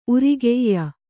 ♪ urigeyya